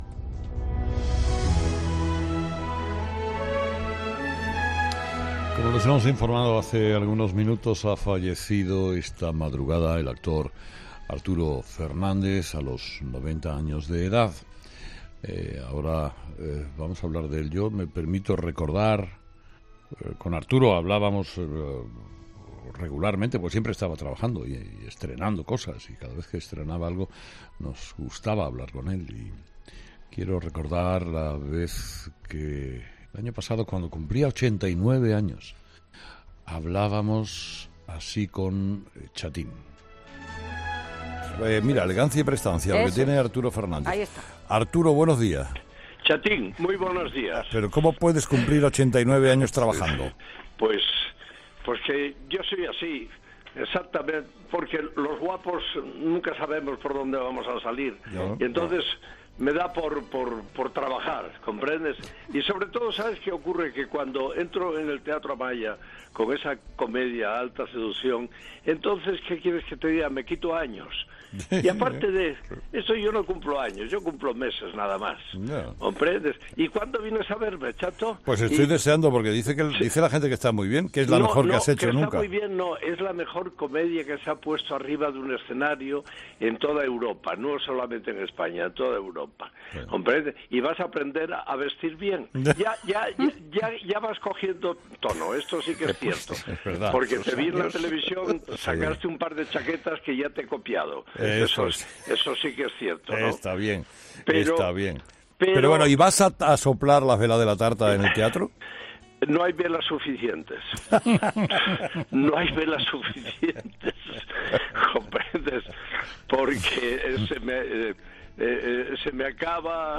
Los colaboradores de Carlos Herrera y el propio comunicador le han rendido un homenaje tras conocer la noticia. “Nos gustaba hablar con él”, ha comenzado a decir Herrera, que ha puesto en antena la última entrevista que le hizo a “chatín” el año pasado coincidiendo con su 89 cumpleaños.